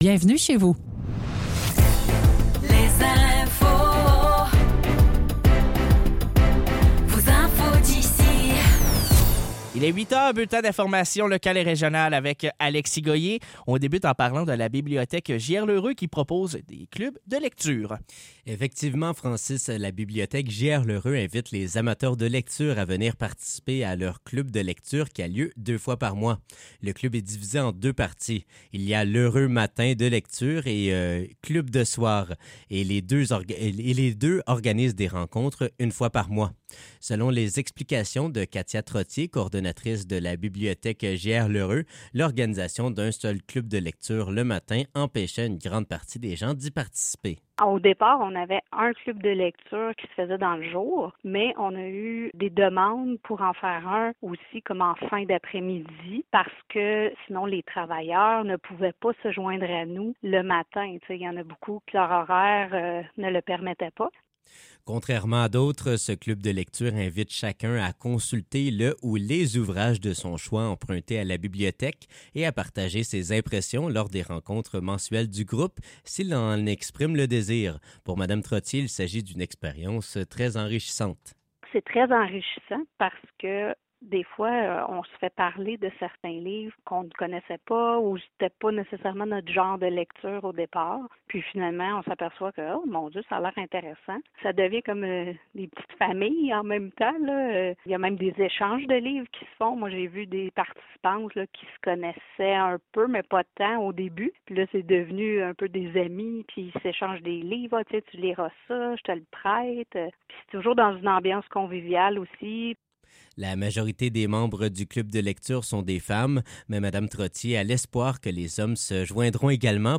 Nouvelles locales - 20 mars 2025 - 8 h